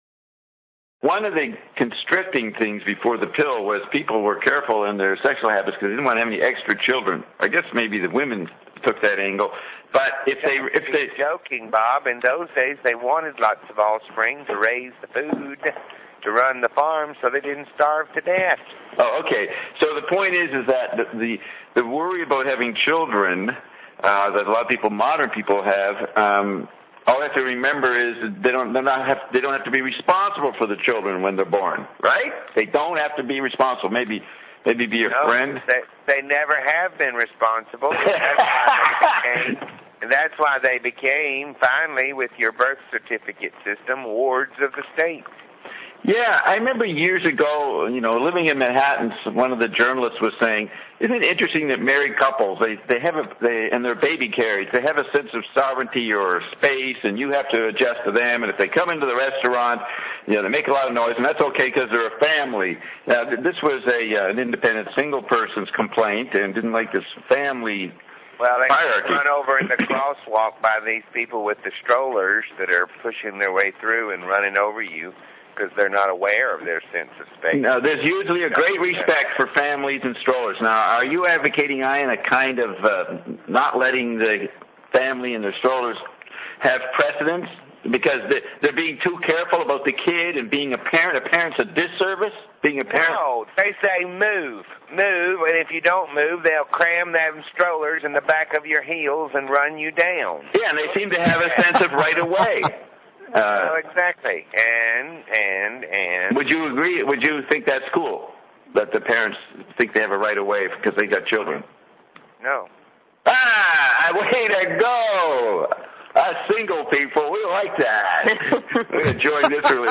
Opening music: